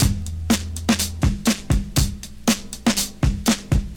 • 121 Bpm Modern Drum Loop A Key.wav
Free drum loop sample - kick tuned to the A note. Loudest frequency: 3060Hz
121-bpm-modern-drum-loop-a-key-mKM.wav